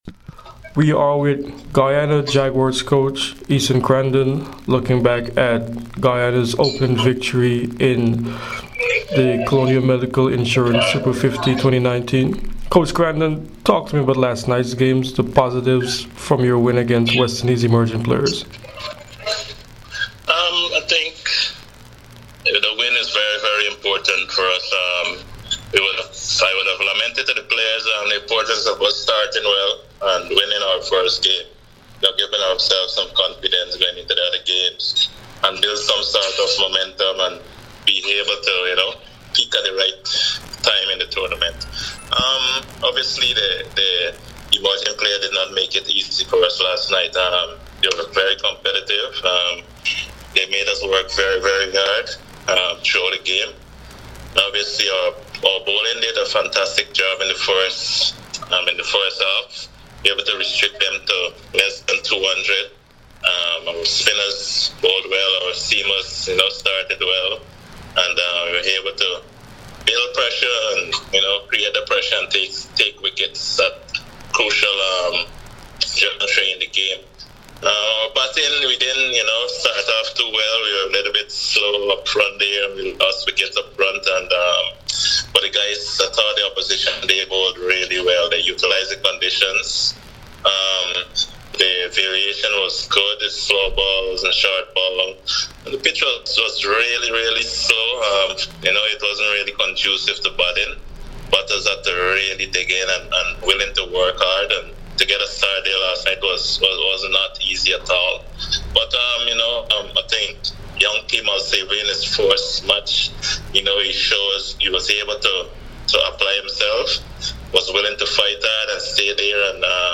Esuan Crandon spoke to CWI Media and press after Zone “B” in the Colonial Medical Insurance Super50 Cup on Thursday at Queen's Park Oval and Brian Lara Cricket Academy